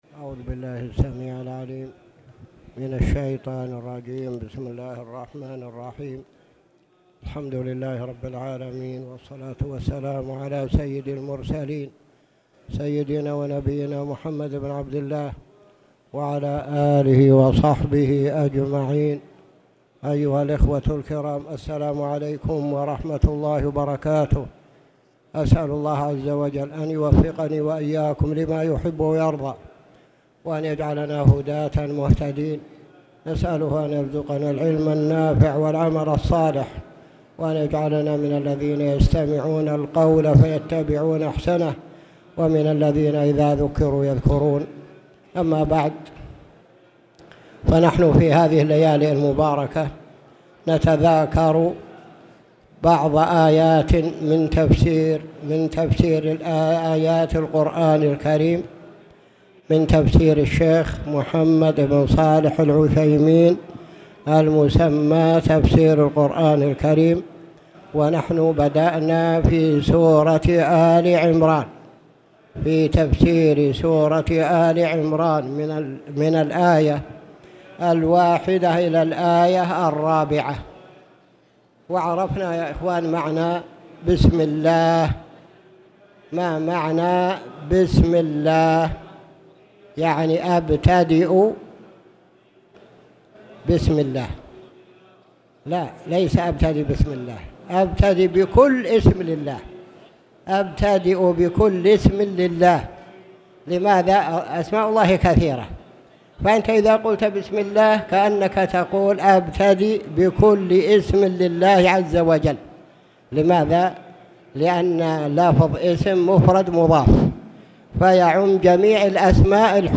تاريخ النشر ٣ ربيع الثاني ١٤٣٨ هـ المكان: المسجد الحرام الشيخ